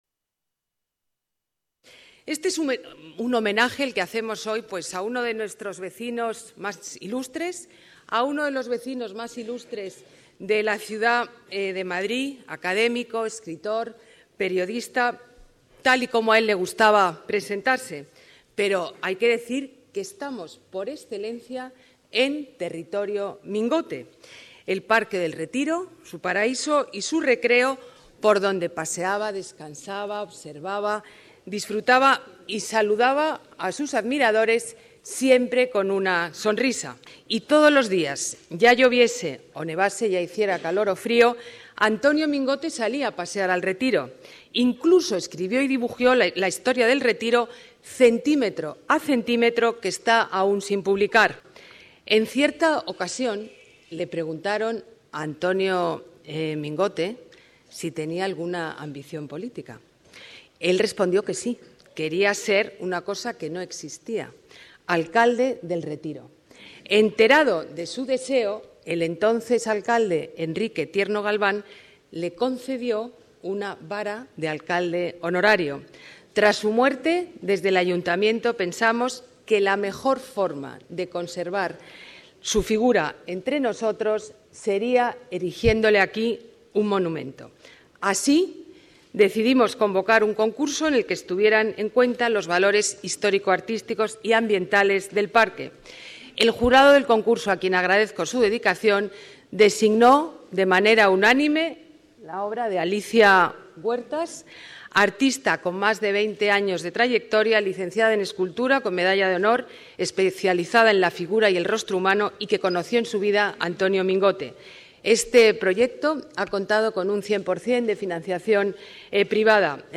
Nueva ventana:Botella en la inauguración de la estatua de Mingote